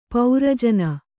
♪ paura jana